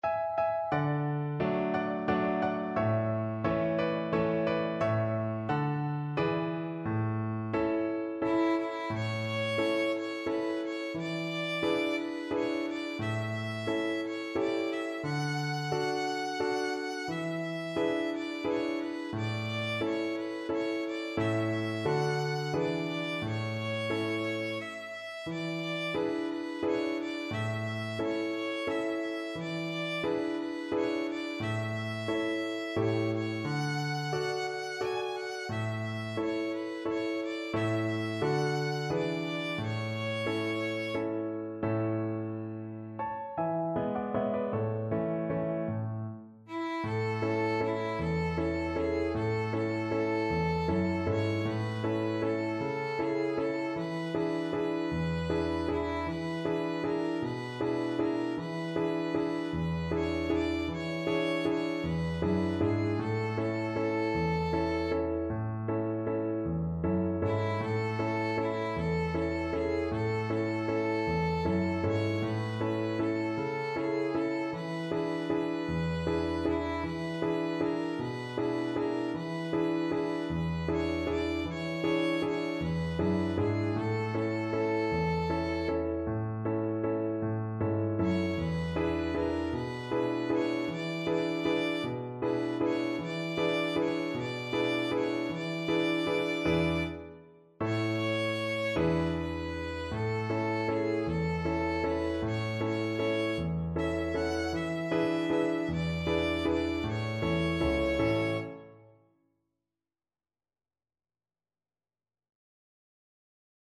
Slow =c.88
3/4 (View more 3/4 Music)
Traditional (View more Traditional Violin Music)
Mariachi Music for Violin